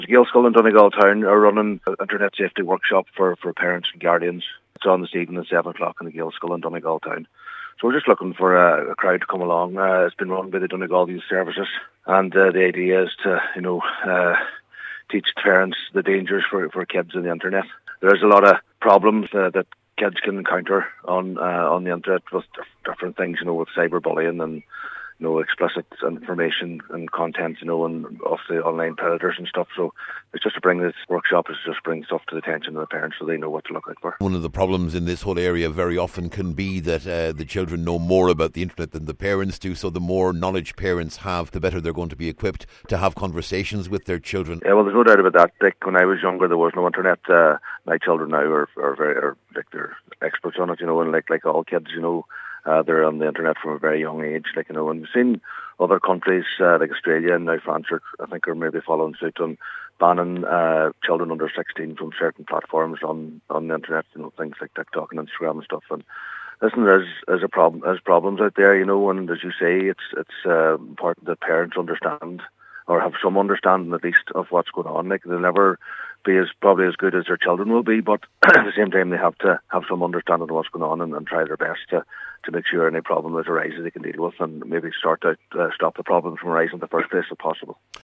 Cllr Jimmy Brogan is urging parents to attend…………